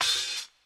Boom-Bap Hat OP 89.wav